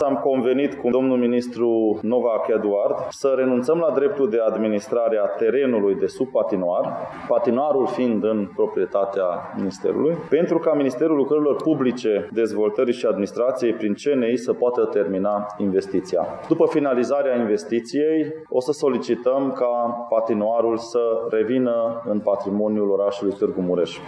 Potrivit primarului municipiului Târgu Mureș, Soós Zoltán, investiția va fi finalizată de Ministerul Lucrărilor Publice, Dezvoltării și Administrației, prin CNI: